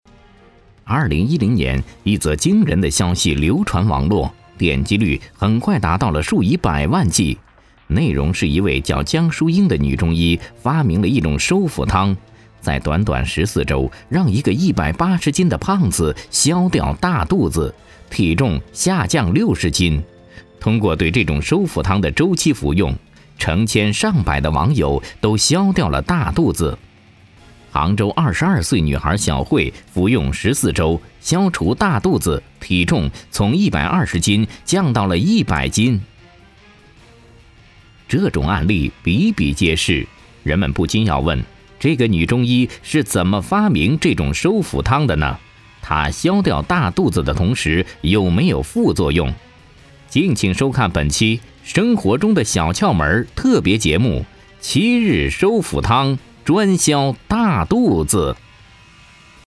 男国102模仿任志宏配音-新声库配音网
男国102_广告_电购_收腹汤（医药任志宏探秘）.mp3